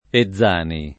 [ e zz# ni ]